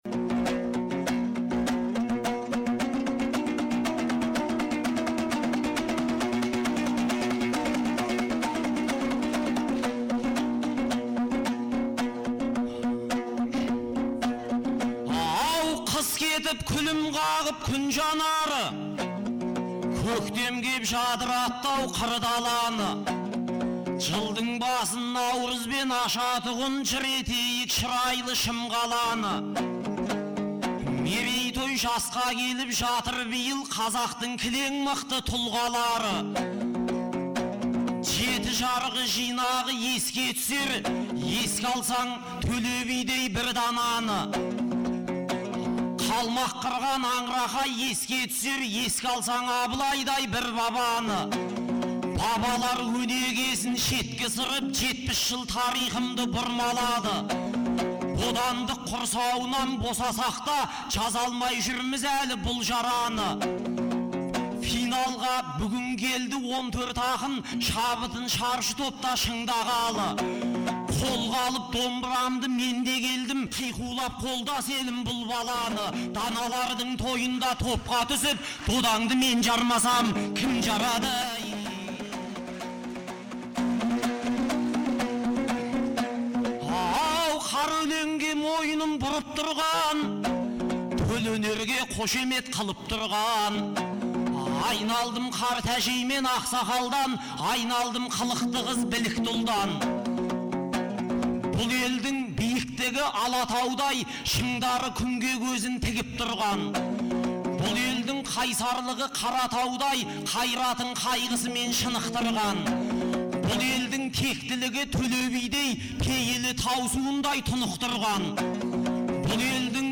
Наурыздың 15-16 күндері Шымкент қаласында екі күнге созылған республикалық «Наурыз» айтысы өткен болатын. 2004 жылдан бері тұрақты өтіп келе жатқан айтыс биылғы жылы Төле бидің 350 және Абылай ханның 300 жылдықтарына арналды.